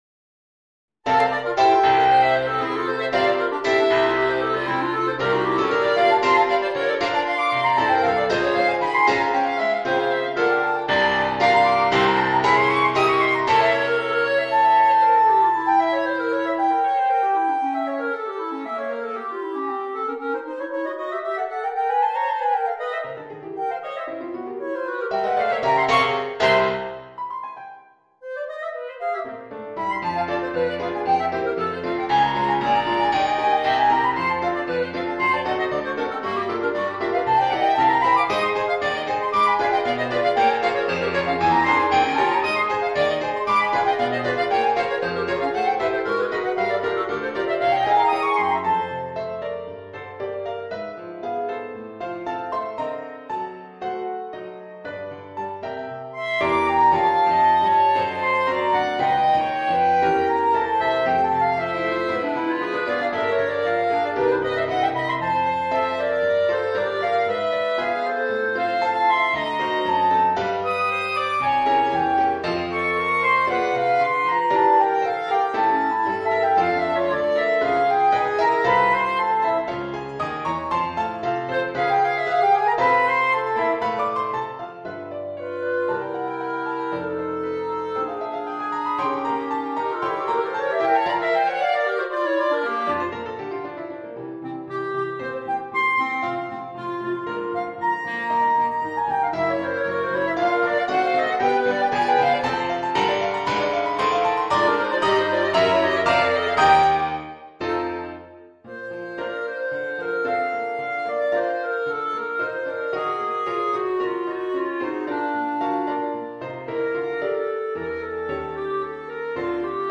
per 2 clarinetti e pianoforte